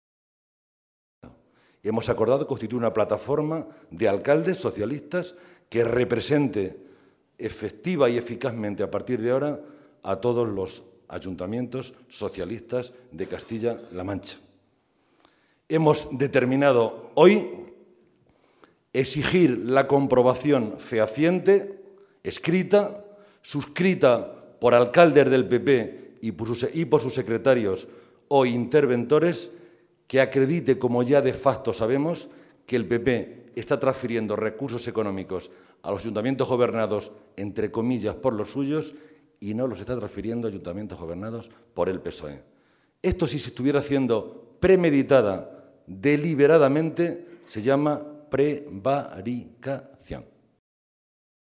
De_Lara-reunion_alcaldes_psoe.mp3